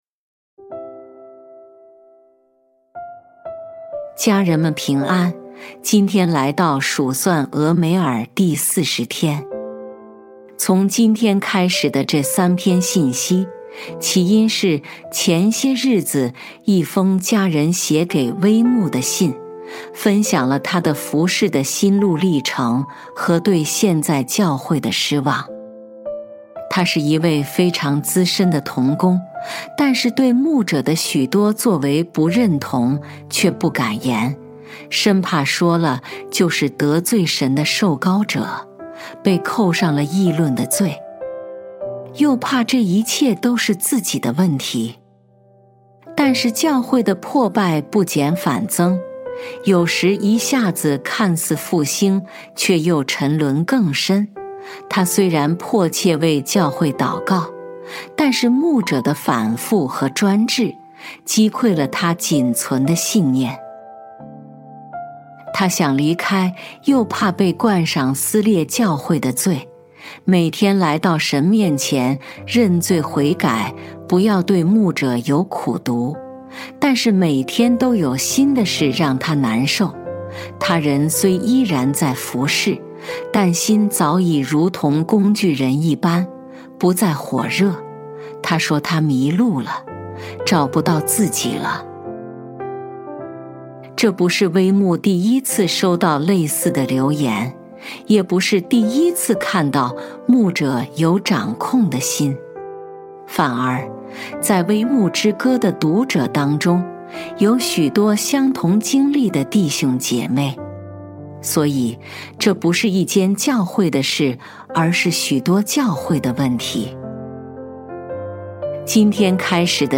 数算俄梅珥第40天祷告